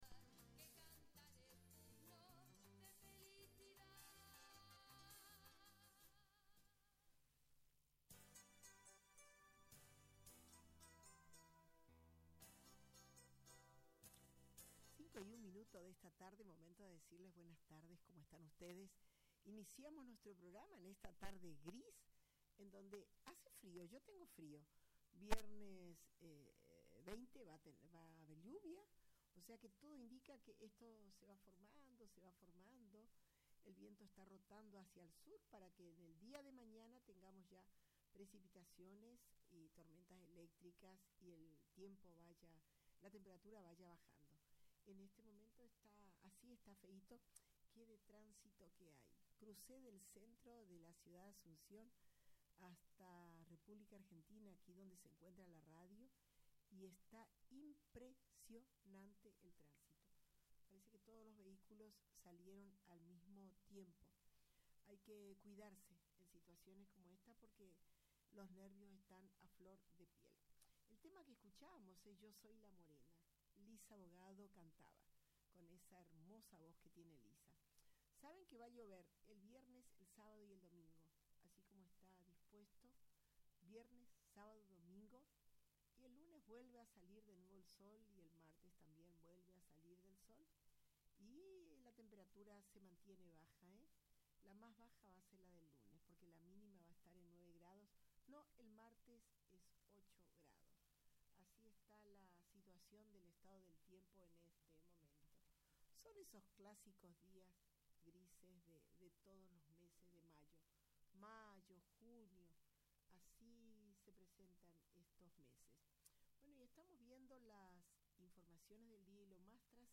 informa desde el Alto Paraná.